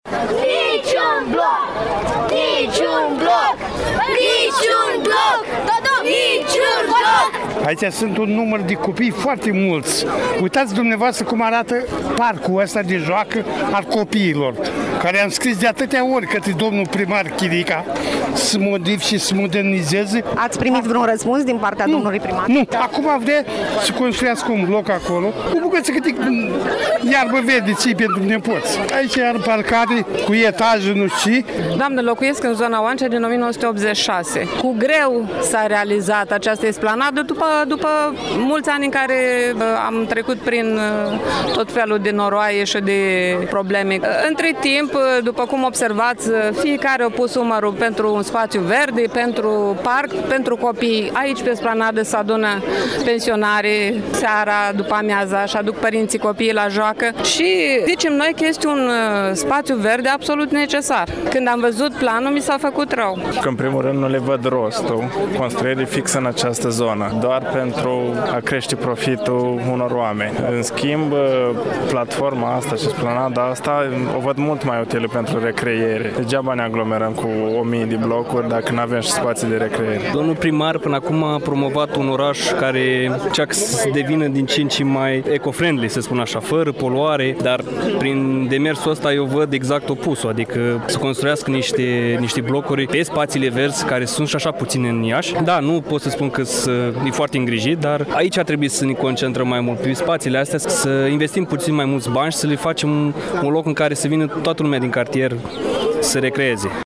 Protest în cartierul Oancea din Iaşi!
Cei mai vocali protestatari au fost copiii, care au scandat „Niciun bloc!”